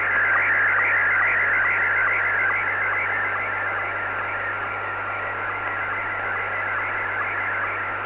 SSTV
Never heard SSTV and you wanna get in the on the listening action? probably the best way to describe the sound - listen for a sound similar to that which Rolph Harris makes when he wobbles a piece of metal or cardboard, only about 3 times as fast.!! =)
When an SSTV station transmits you'll hear a few seconds of what you might call the "Front Porch", this is where the SSTV program sends out a message to the receiving station saying "get ready to sync and start the image, here it comes !" and then starts the image.